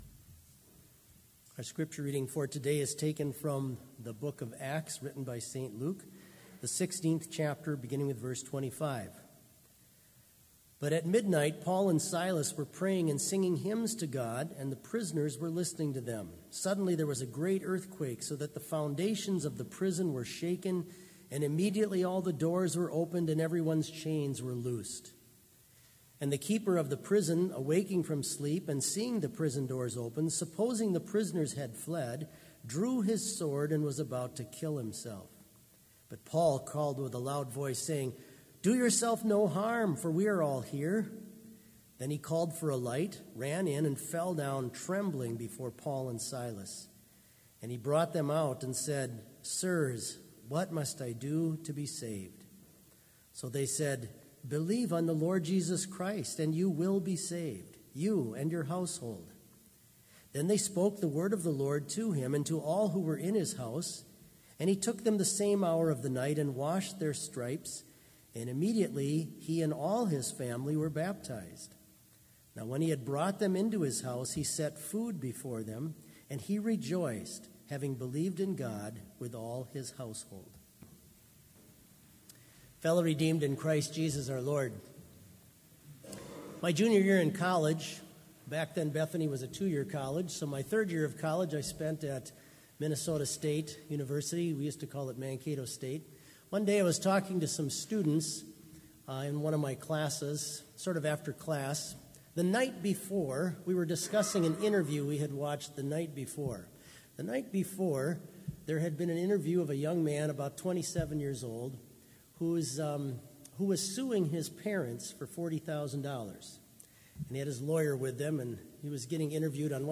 Complete service audio for Chapel - January 25, 2019